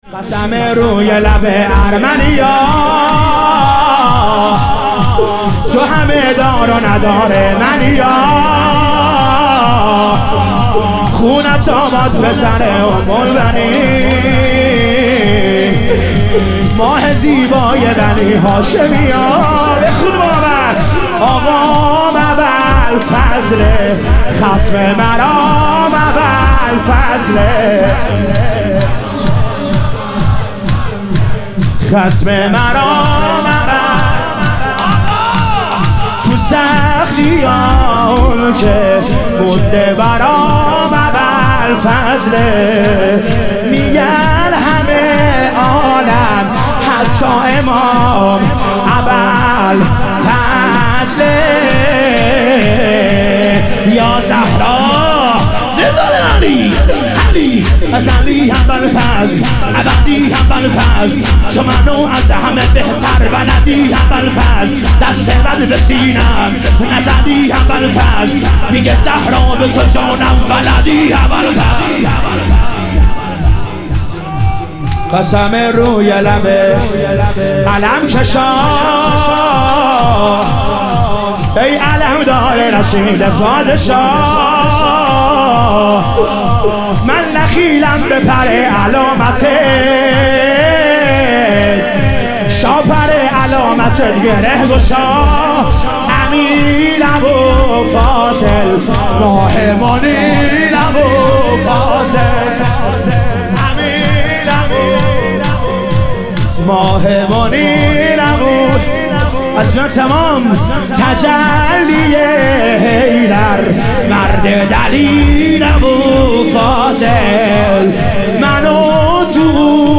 حرکت کاروان سیدالشهدا علیه السلام - شور